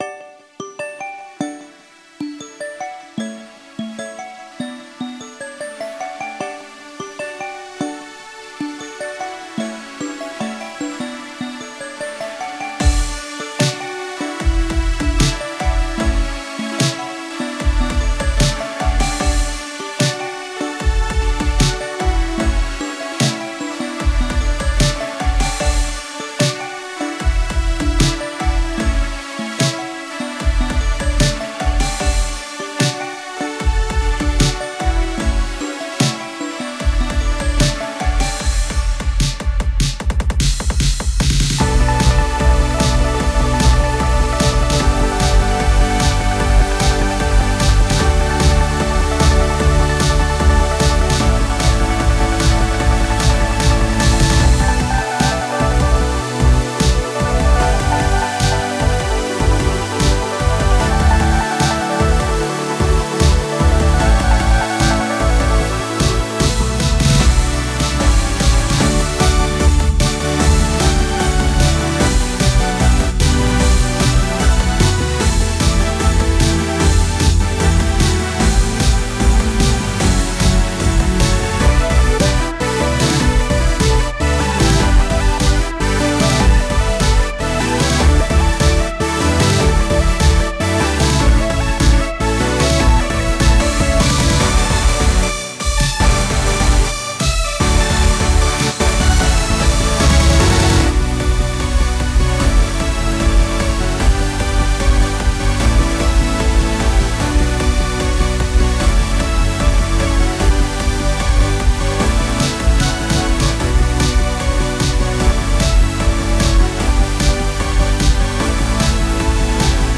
全体的にはEDMっぽいPOP曲なのかなと思ったり。